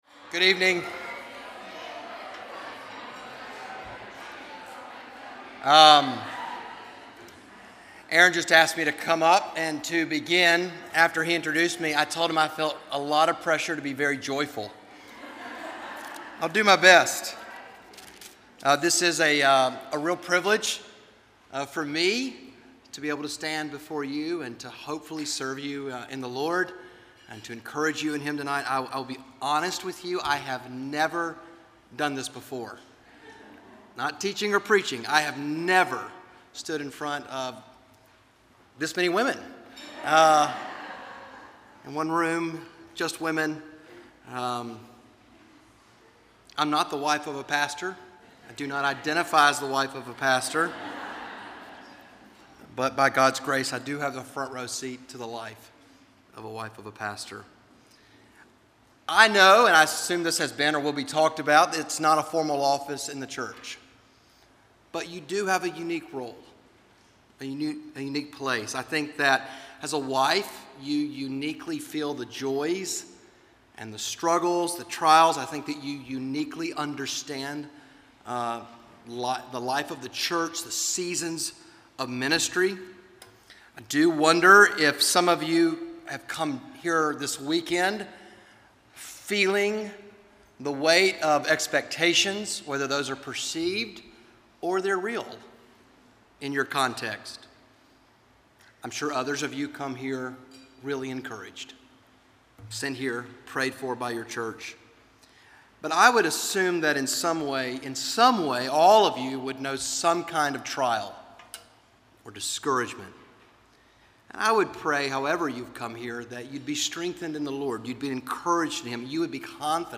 Audio recorded at Feed My Sheep for Pastors Wives Conference 2022.